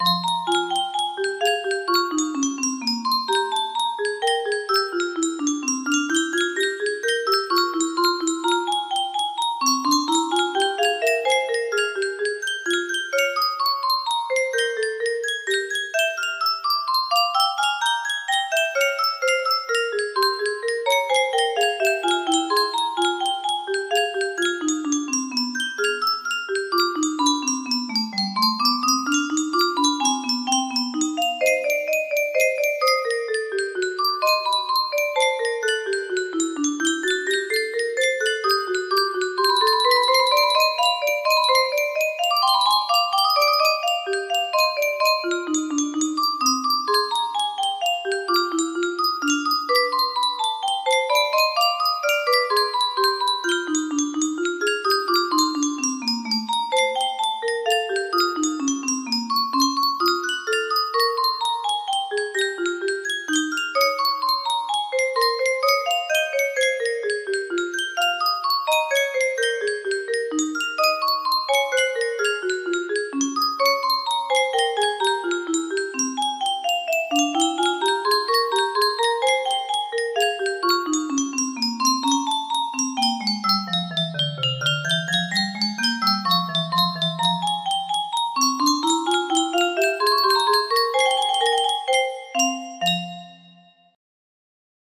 Bach Invention No. 9 in F Minor BWV 780 music box melody
Full range 60